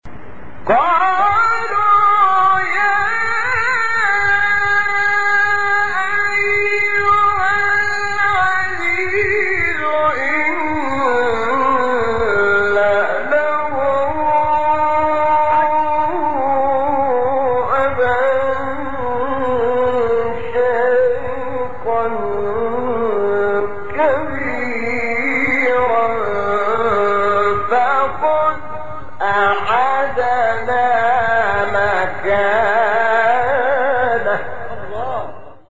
سوره : یوسف آیه: 78 استاد : شحات محمد انور مقام : رست قبلی بعدی